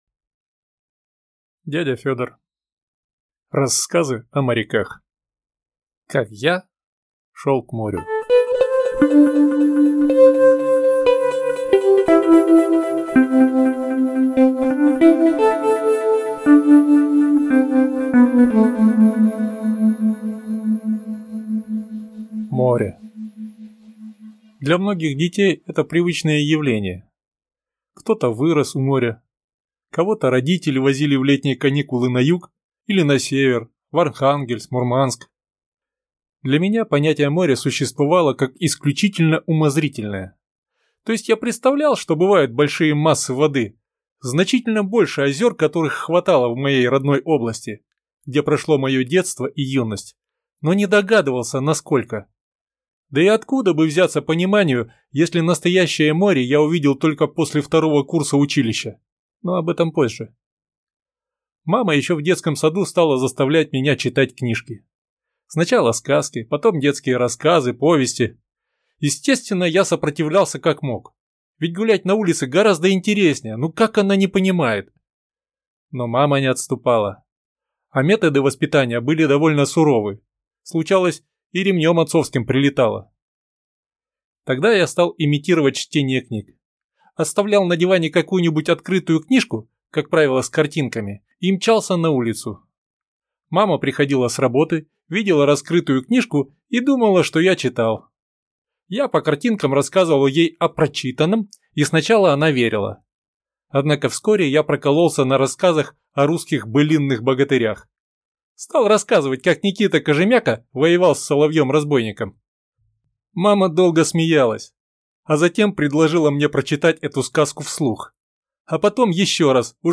Аудиокнига Рассказы о моряках…